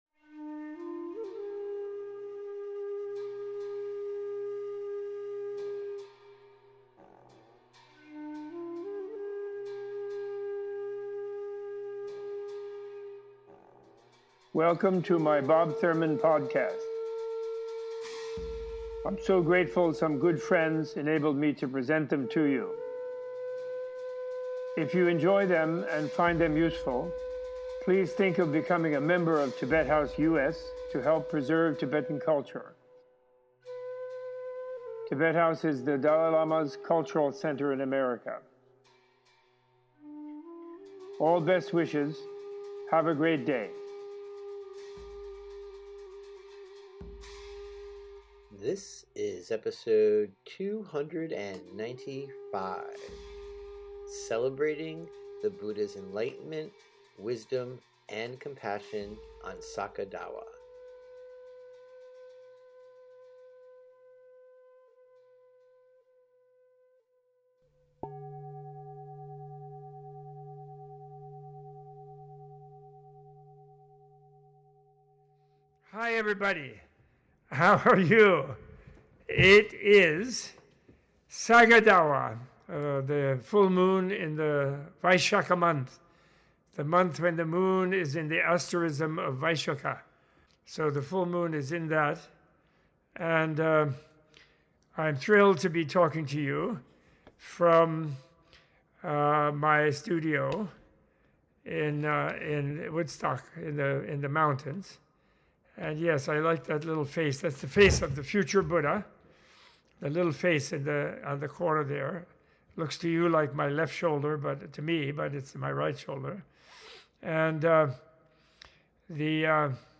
In this message celebrating the full moon day of celebration of the birth, enlightenment and passing of the historical Buddha, Professor Thurman gives an extended teaching on how Saka Dawa is celebrated by Tibetans across the world. Saka Dawa, named for the star, Vishakha, prominent during the fourth month in the Tibetan lunar calendar, is almost the same as the Theravadin observance of Vesak.